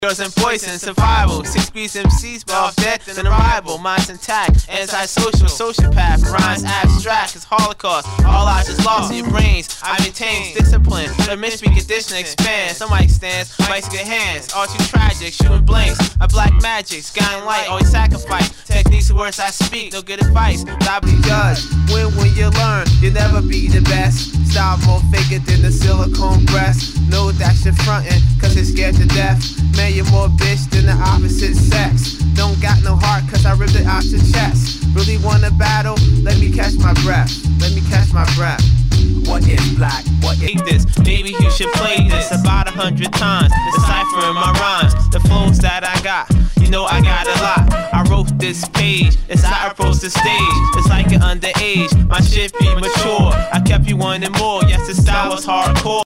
HIPHOP/R&B
ナイス！アングラ！